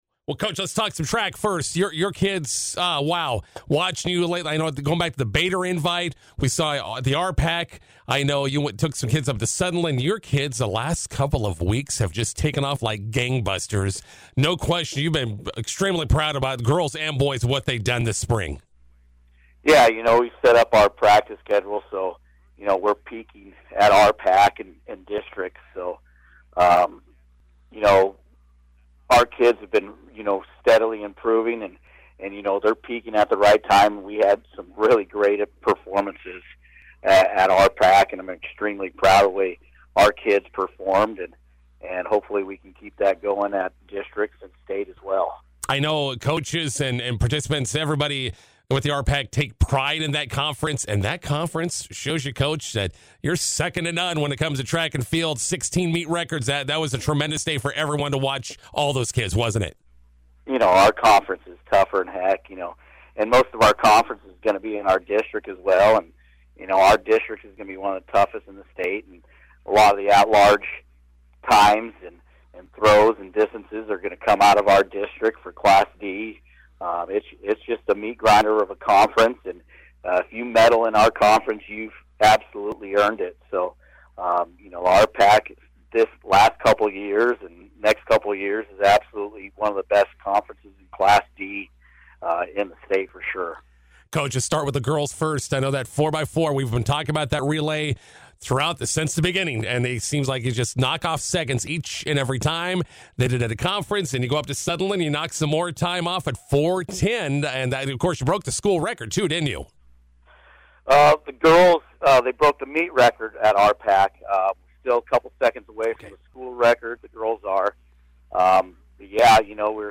INTERVIEW: Arapahoe Track and Field preparing for district meet next week in Bertrand.